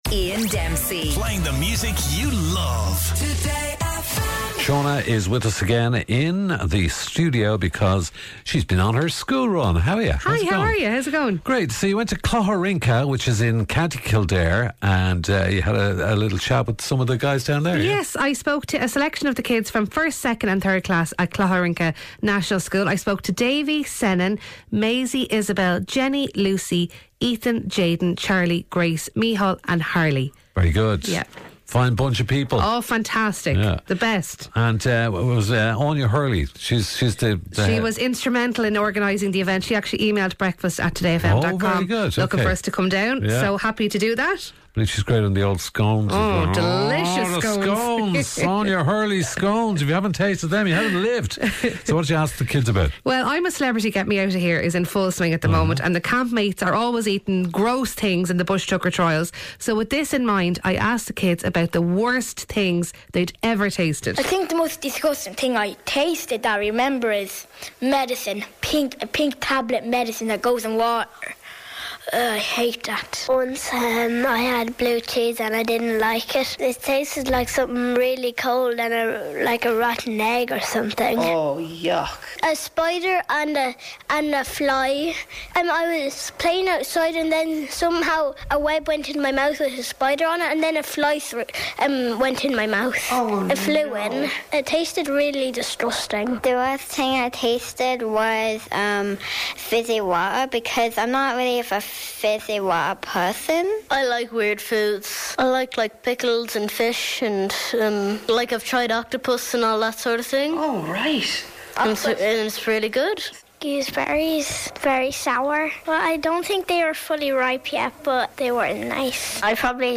With Iano at the helm, the Breakfast Show brings you up to speed on everything. News, sport, guests, giveaways, listener interaction and music - not to mention the legendary Gift Grub - all add to the mix of this 2-hour radio rollercoaster.